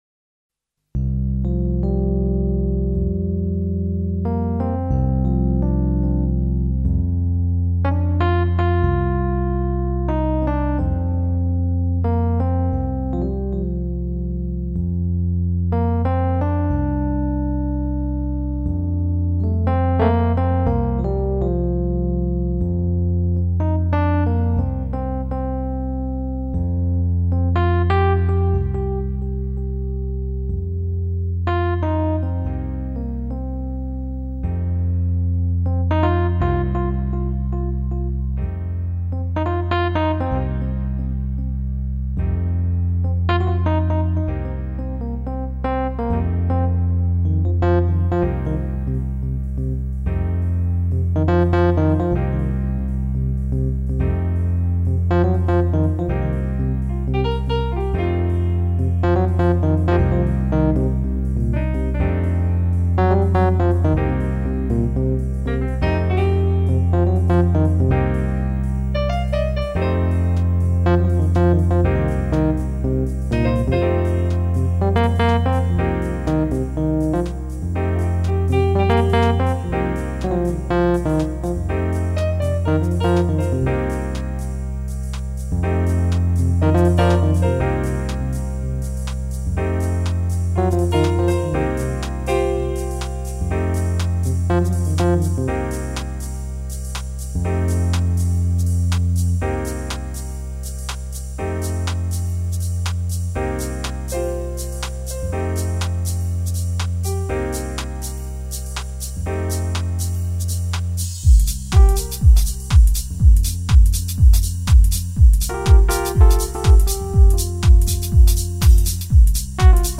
NEW AGE / LOUNGE